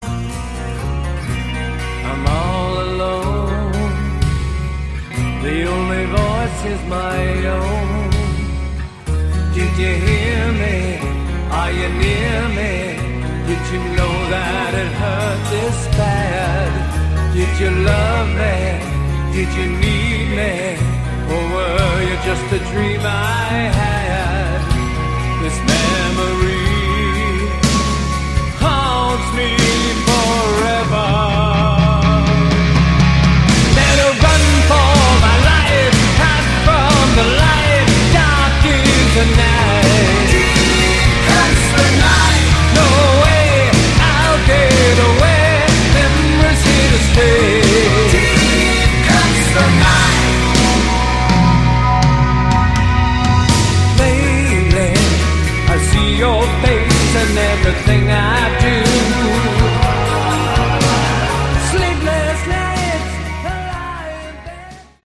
Category: Hard Rock
vocals
guitar, vocals
bass, vocals, keyboards
drums